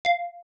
Ping.wav